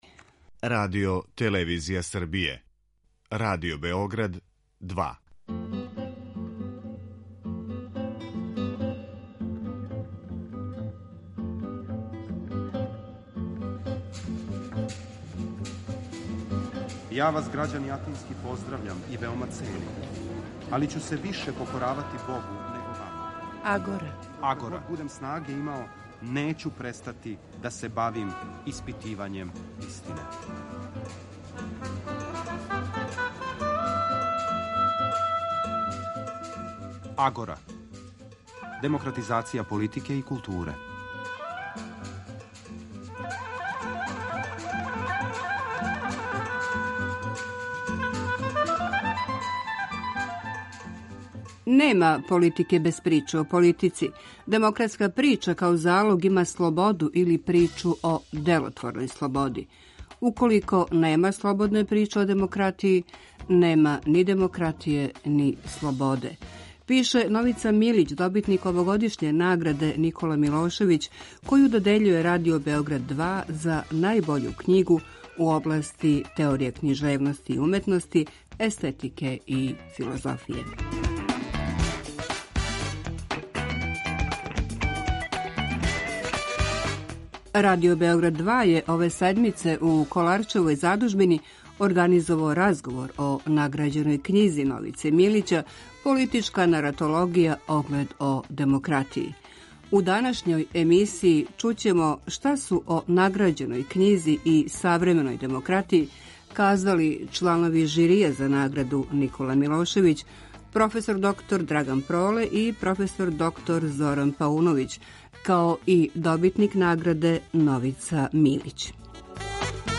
Радио-магазин који анализира феномене из области политичког живота, филозофије, политике и политичке теорије.
Радио Београд 2 је ове седмице у Коларчевој задужбини организовао разговор о награђеној књизи